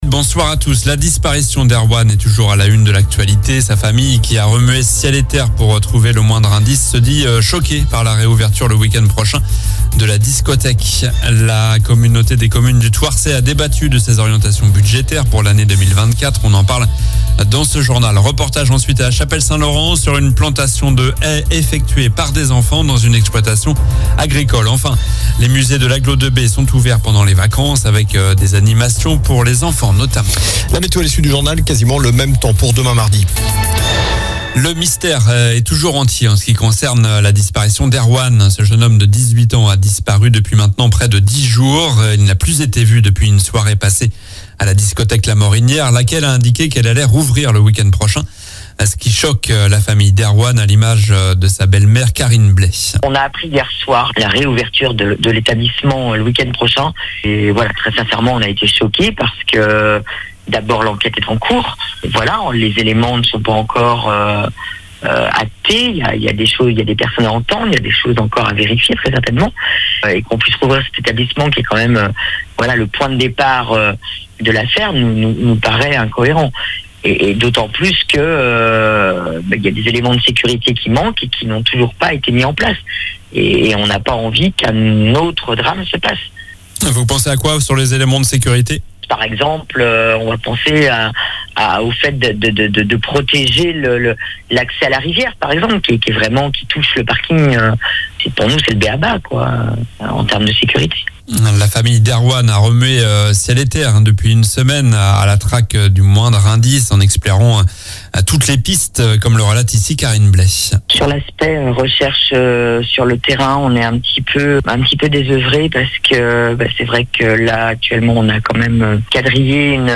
L'info près de chez vous
- La communauté du Thouarsais a débattu de ses orientations budgétaires - Reportage ensuite à la Chapelle St Laurent sur une plantation de haies - Les musées de l'Agglo2B sont ouverts pendant les vacances... 0:00 14 min 39 sec